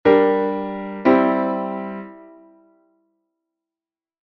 Esta cadencia é unha Semicadencia. armadura un bemol, dous acordes: sol-re-sol-si; sol-do-mi-sol
10._semicadencia_en_Fa_M.mp3